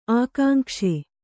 ♪ ākāŋkṣe